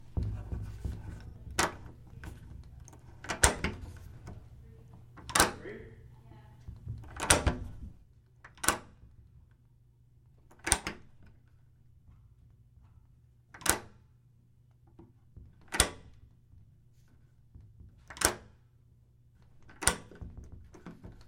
钥匙开锁门的困扰
描述：钥匙被锁上并解锁门
Tag: 打开 钥匙 锁定门 解锁 解锁门 门解锁 门把手 门把手车削